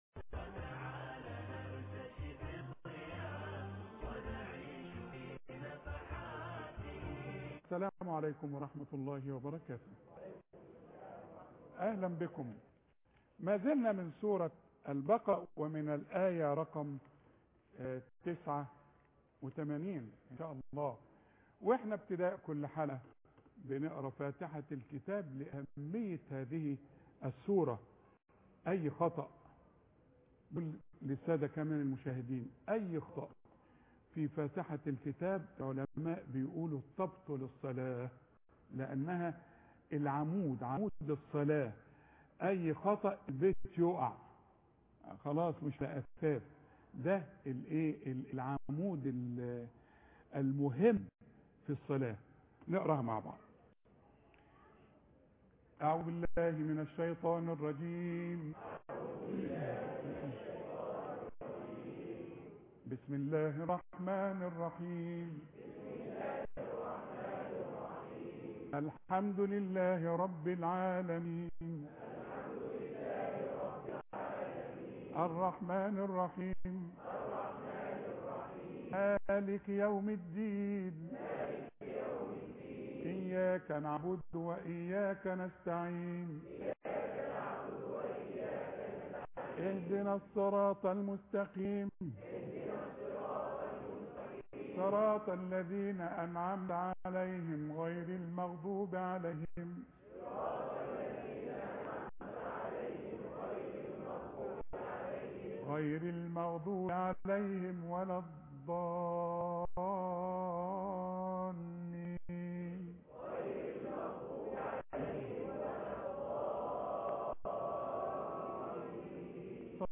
قراءة من الاية 89 فى سورة البقرة